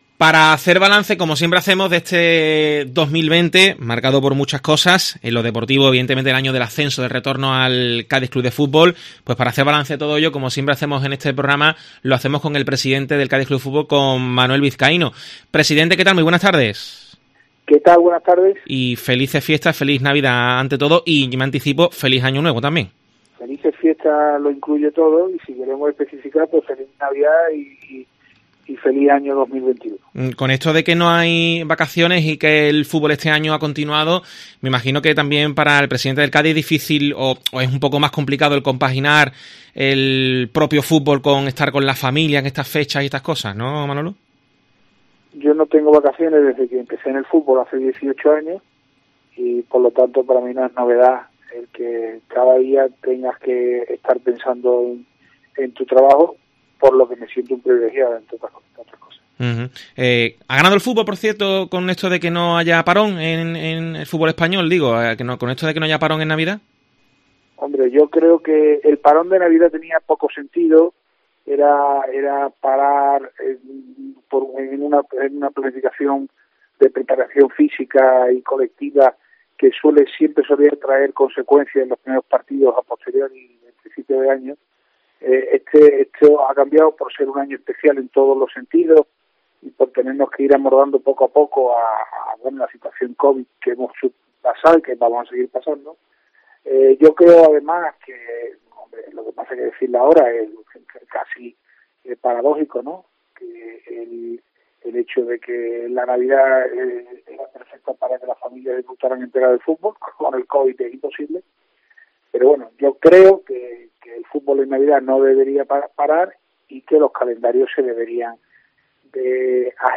Análisis en los micrófonos de Deportes COPE Cádiz en los que ha destacado la importancia de lo logrado.